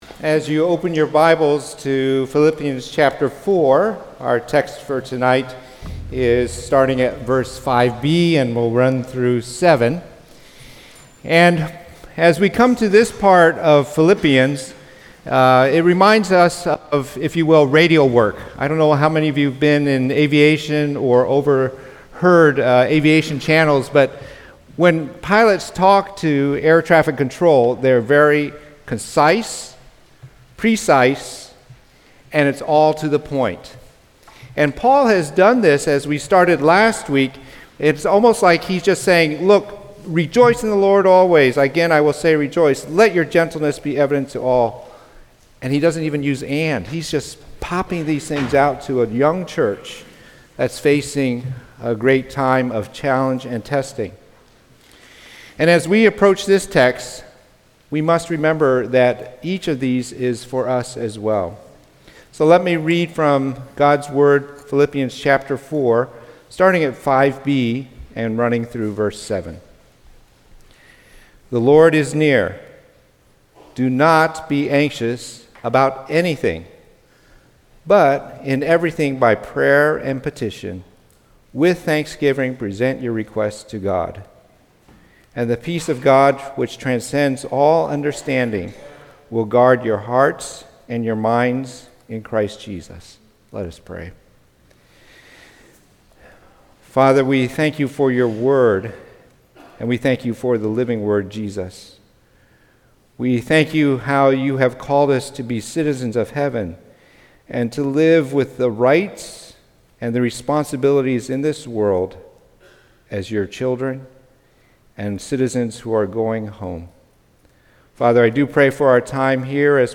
From Series: "Sunday Sermons"